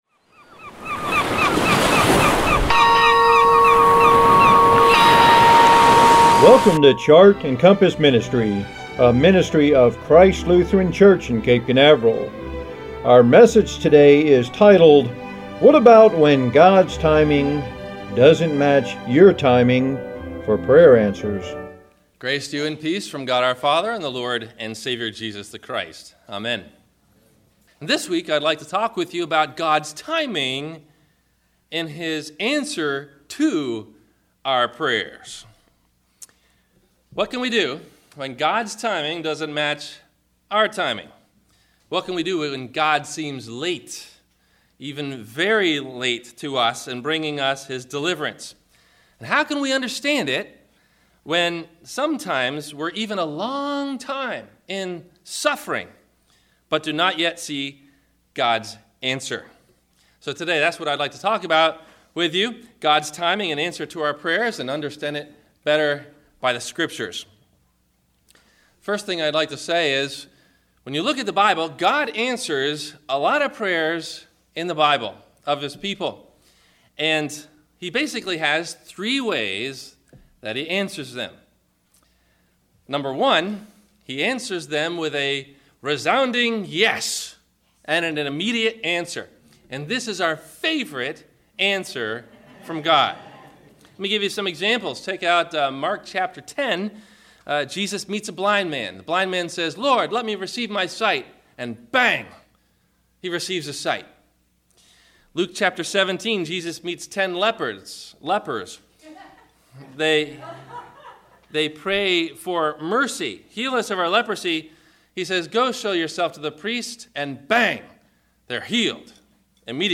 Have a Comment or Question about the Sermon?